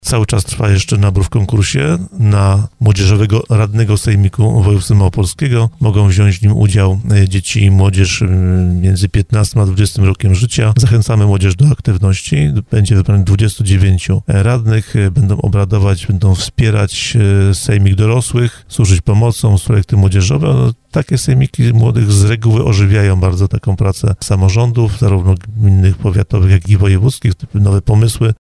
O tej inicjatywie mówił w audycji Słowo za Słowo Wojciech Skruch Wiceprzewodniczący Sejmiku Województwa Małopolskiego.